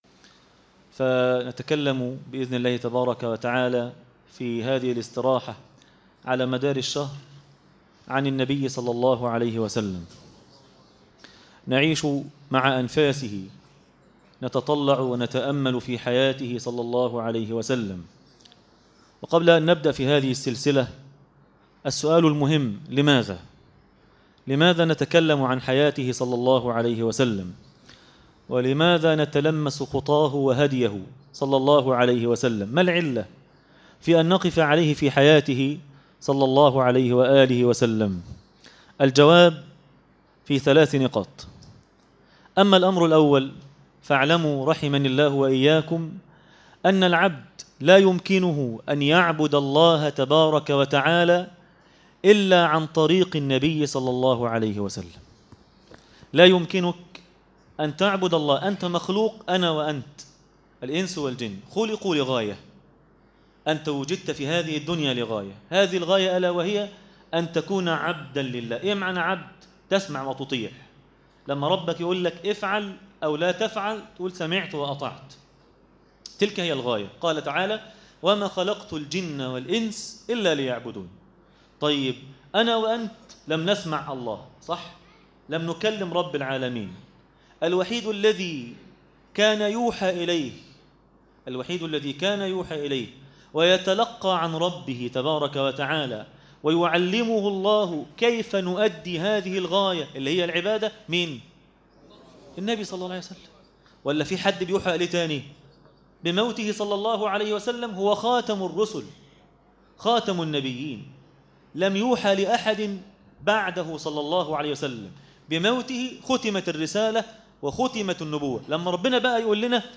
عنوان المادة درس التراويح - ليلة 2 رمضان 1437 هـ